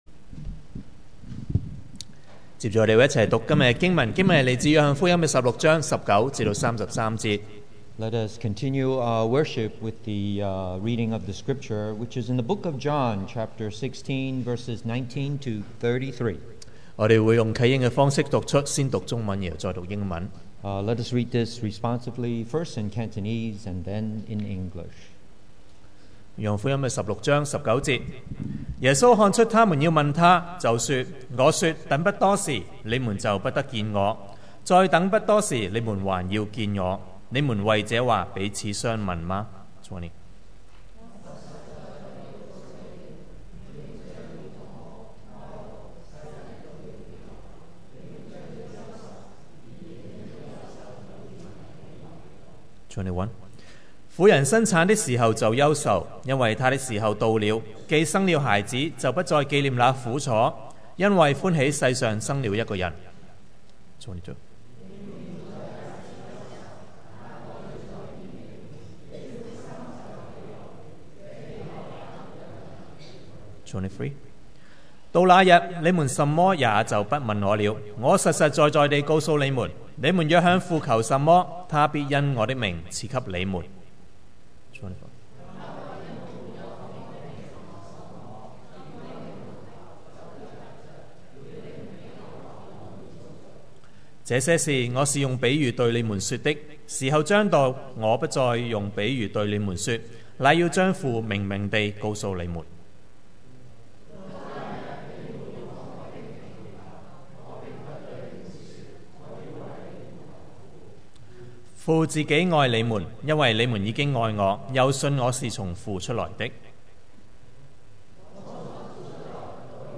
2018 sermon audios
Service Type: Sunday Morning